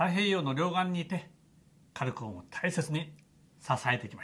FormerJapanesePrimeMinister
voice